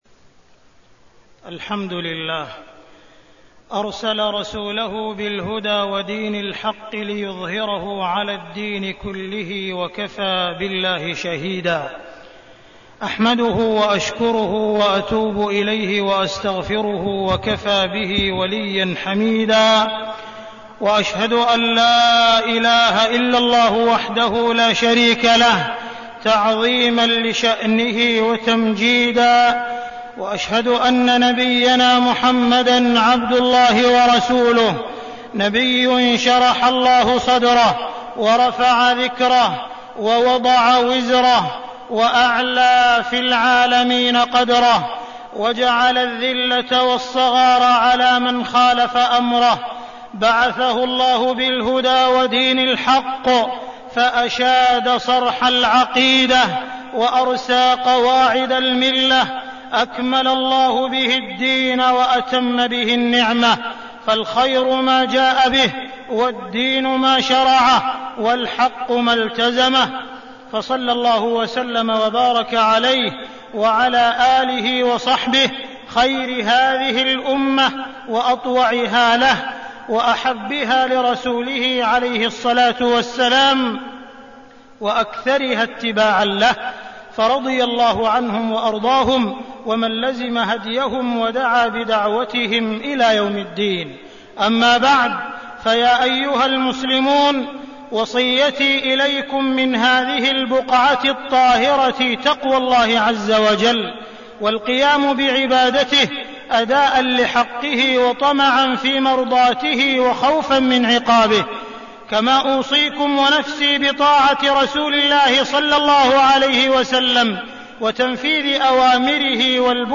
تاريخ النشر ١٦ ربيع الأول ١٤١٩ هـ المكان: المسجد الحرام الشيخ: معالي الشيخ أ.د. عبدالرحمن بن عبدالعزيز السديس معالي الشيخ أ.د. عبدالرحمن بن عبدالعزيز السديس محبة الله ورسوله والإتباع